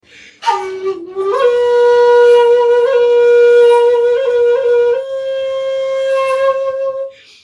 Shakuhachi 57